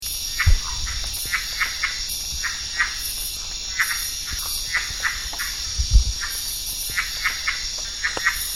Common names : Red-eyed Leaf-frog / Red-eyed Treefrog
Calling generally begins about dusk and the call of the male is a single or double 'chuck' or 'cluck'.
Calling individuals can congregate into a chorus of over a few hundred individuals.
A_callidryas.mp3